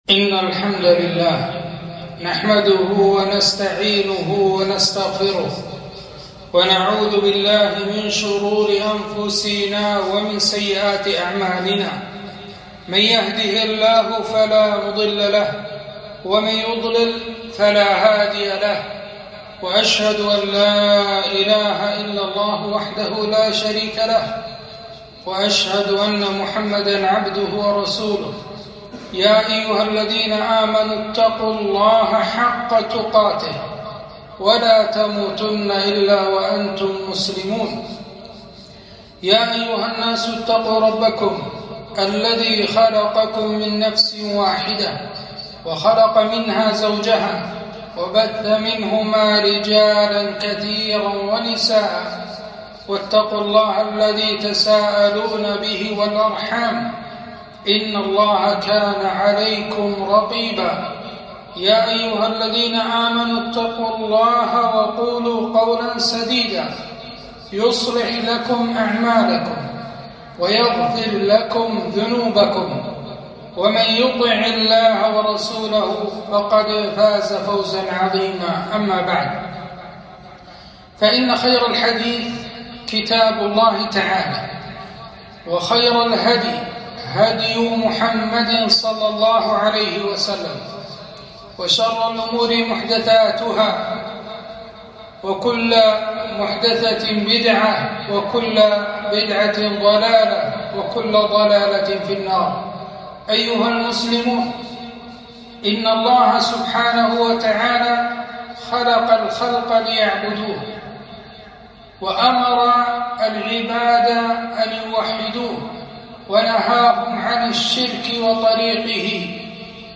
خطبة - ومن يشرك بالله فقد ضل ضلالا بعيدا - دروس الكويت